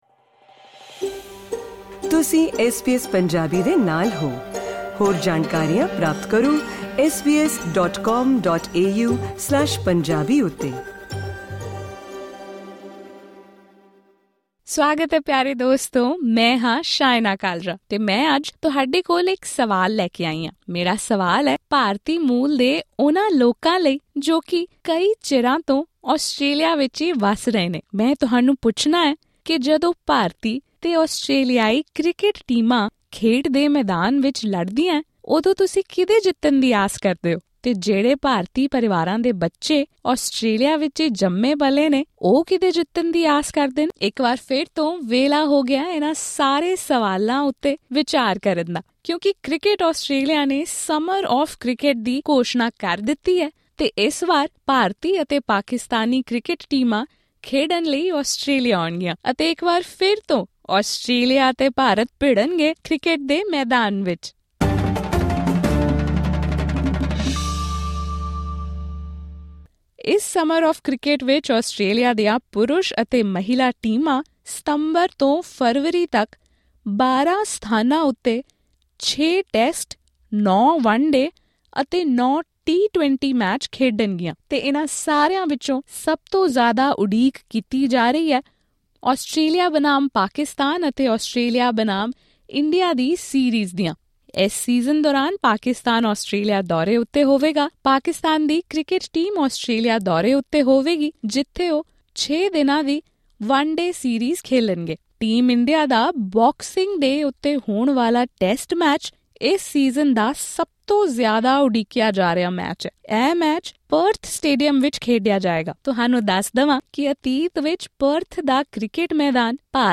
ਆਉਣ ਵਾਲੀਆਂ ਗਰਮੀਆਂ ਦੌਰਾਨ ਖੇਡੇ ਜਾਣ ਵਾਲੇ ਕ੍ਰਿਕਟ ਸੀਜ਼ਨ ਦਾ ਭਰਪੂਰ ਅਨੰਦ ਉਠਾਉਣ ਲਈ ਤਿਆਰ ਹੋ ਜਾਓ ਕਿਉਂਕਿ ਆਸਟਰੇਲੀਆ ਕੁੱਲ 24 ਮੈਚ ਖੇਡਣ ਵਾਲਾ ਹੈ। ਸਭ ਤੋਂ ਰੋਮਾਂਚਕ ਮੈਚਾਂ ਵਿੱਚ ਭਾਰਤੀ ਟੈਸਟ ਅਤੇ ਪਾਕਿਸਤਾਨ ਵਨ-ਡੇਅ ਸੀਰੀਜ਼ ਸ਼ਾਮਲ ਹਨ। ਐਸਬੀਐਸ ਪੰਜਾਬੀ ਨਾਲ ਵਿਸ਼ੇਸ਼ ਗੱਲਬਾਤ ਦੌਰਾਨ ਆਸਟ੍ਰੇਲੀਅਨ ਖਿਡਾਰੀਆਂ ਨੇ ਕਿਹਾ ਕਿ ਉਹ ਭਾਰਤ ਦੇ ਤੇਜ਼ ਗੇਂਦਬਾਜ਼ਾਂ, ਖਾਸ ਕਰ ਜਸਪ੍ਰੀਤ ਬੁਮਰਾ ਉੱਤੇ ਨਜ਼ਰ ਰੱਖਣਗੇ।